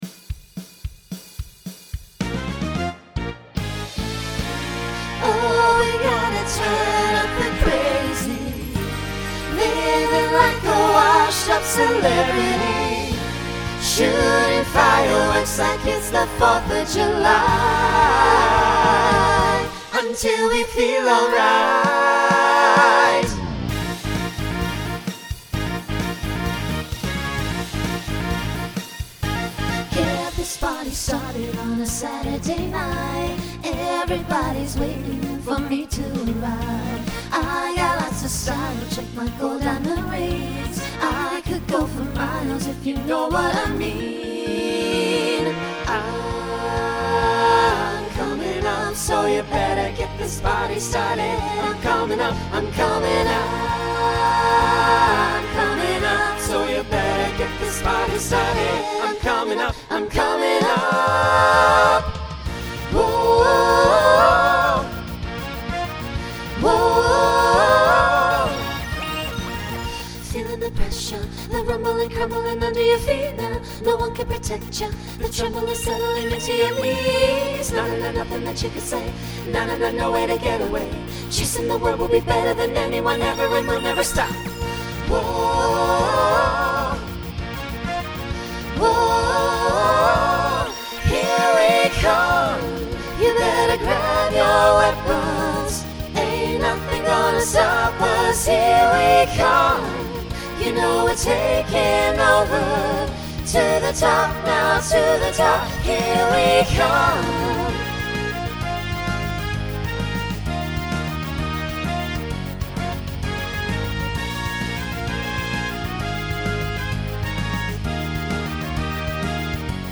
Pop/Dance , Rock Instrumental combo
Story/Theme Voicing SATB